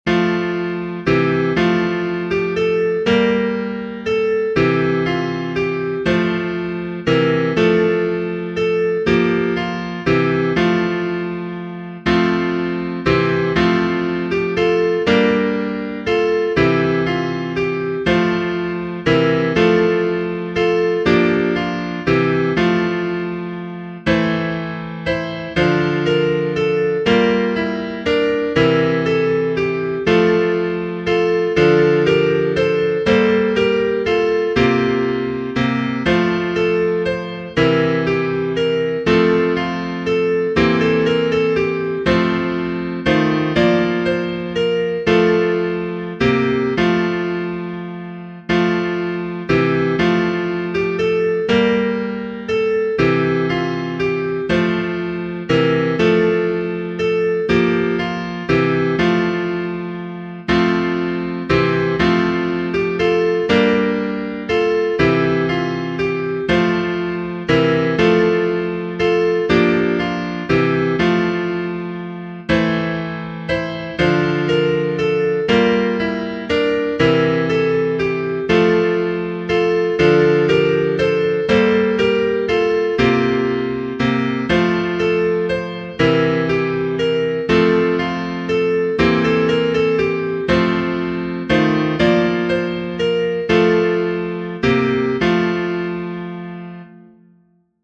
Prichard, R. H. Genere: Religiose Text by Charles Wesley Come, thou long expected Jesus, born to set thy people free; from our fears and sins release us, let us find our rest in thee.